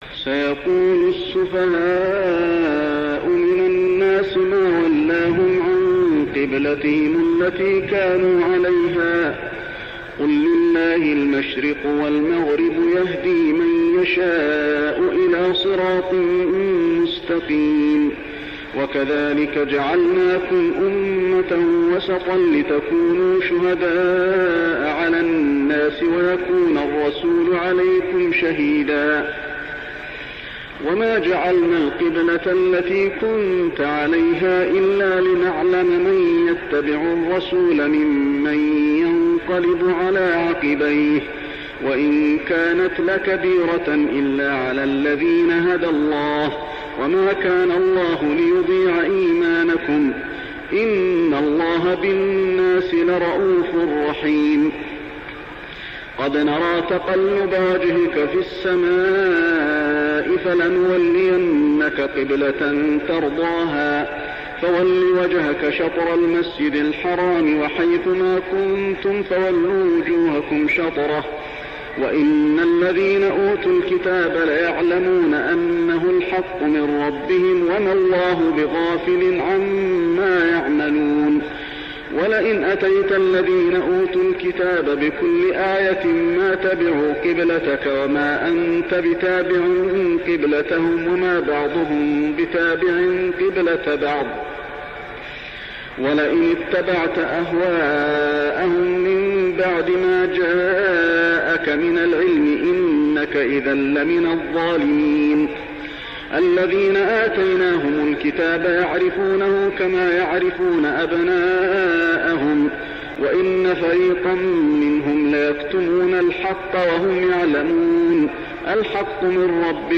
صلاة التراويح ليلة 2-9-1409هـ سورة البقرة 142-202 | Tarawih prayer Surah Al-Baqarah > تراويح الحرم المكي عام 1409 🕋 > التراويح - تلاوات الحرمين